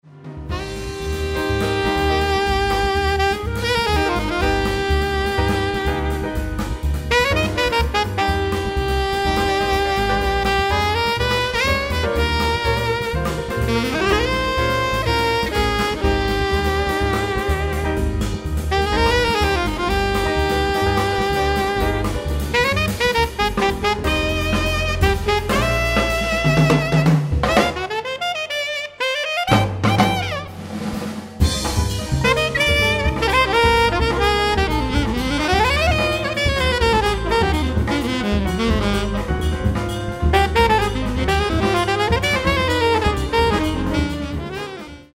baritone saxophones, flute, alto flute,
clarinet and bass clarinet
keyboards
drums
acoustic bass